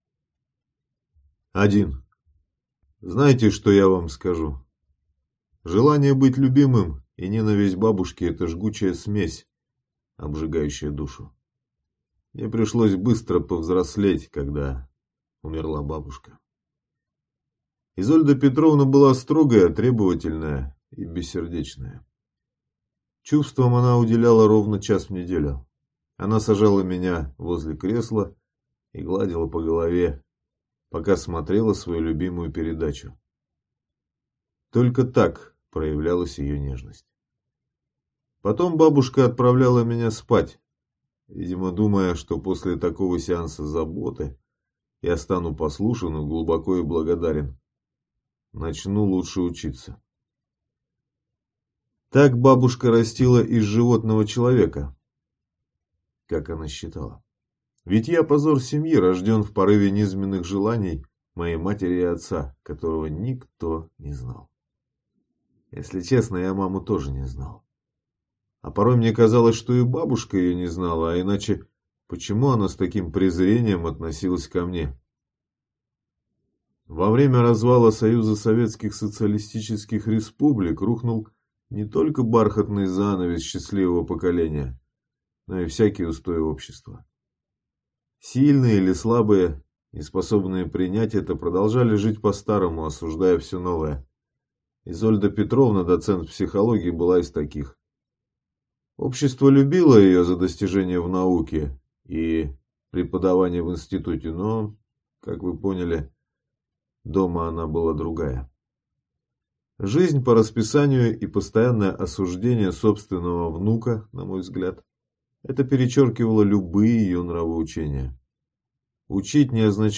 Аудиокнига Животное | Библиотека аудиокниг
Прослушать и бесплатно скачать фрагмент аудиокниги